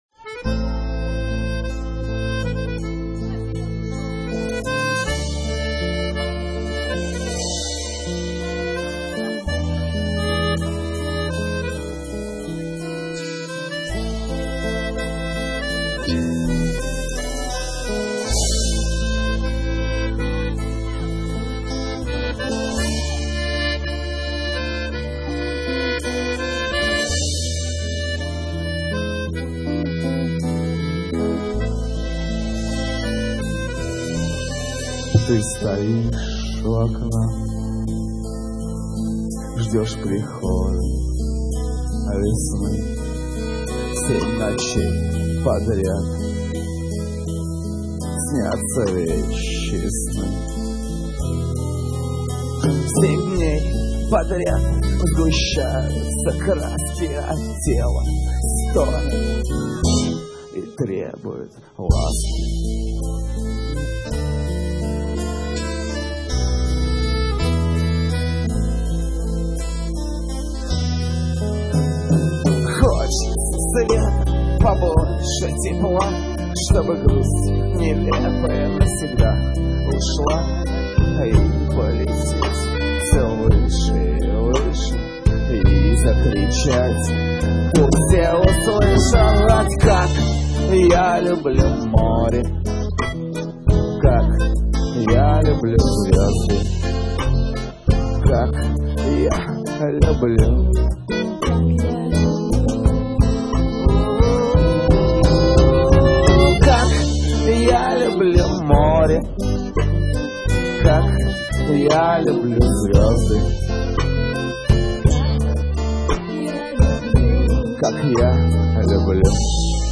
Стиль можно охарактеризовать как "ска-реггей-фолк-рокопоп".
гитара
аккордеон
бас-гитара
барабаны
бэк-вокал